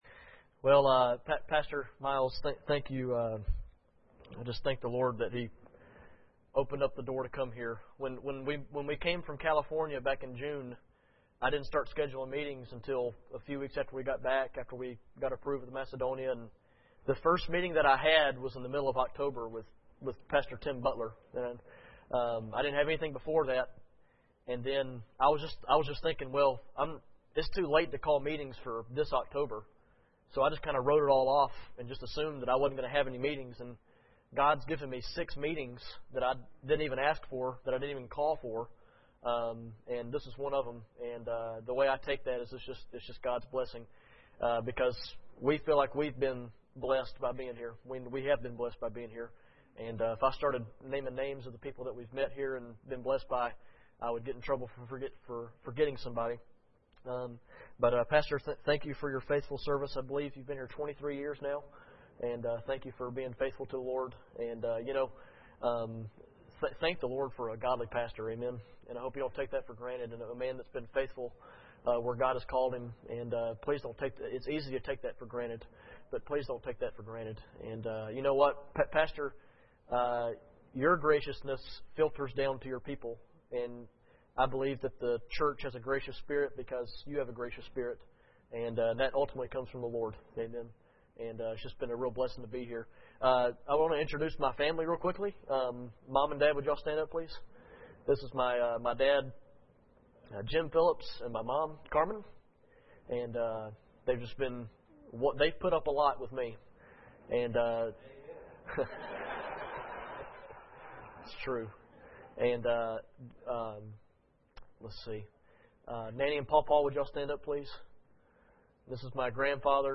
Series: 2009 Missions Conference
Service Type: Sunday Evening